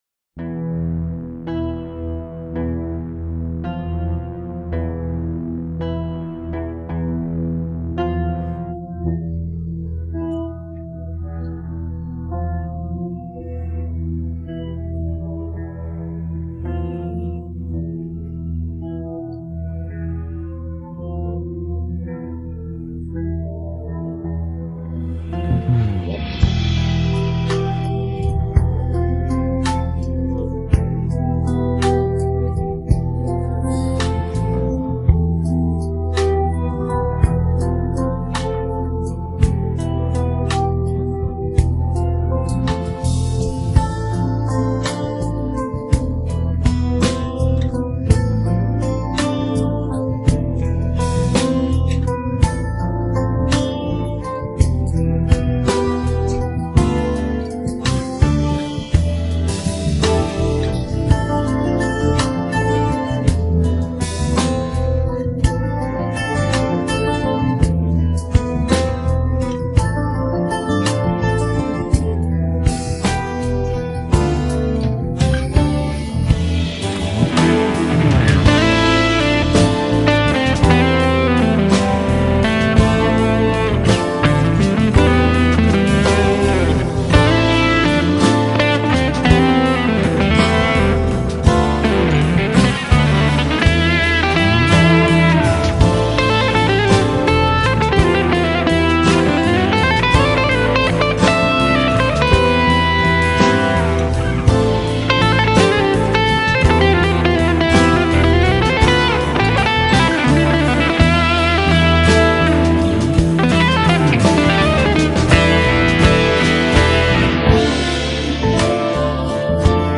پخش نسخه بی‌کلام
download-cloud دانلود نسخه بی کلام (KARAOKE)